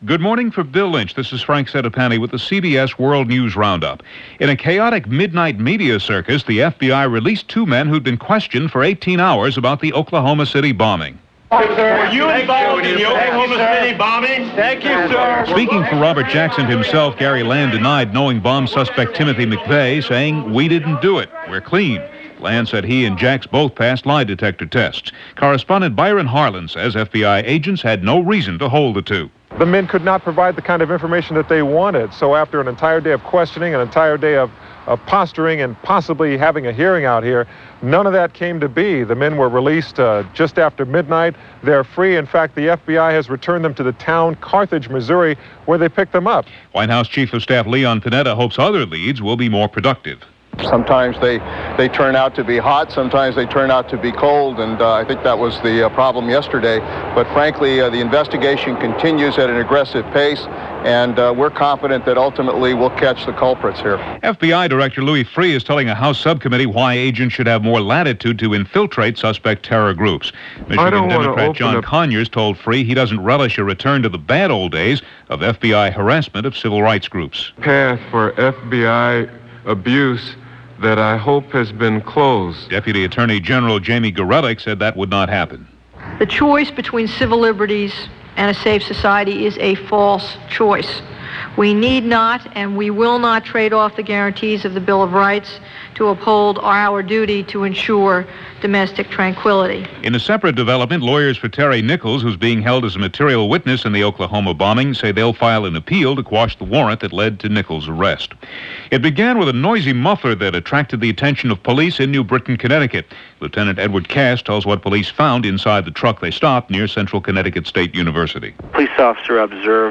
And that’s just a small slice of what went on, this rather fractured May 3, 1995 from The CBS World News Roundup.
News-for-May-31995.mp3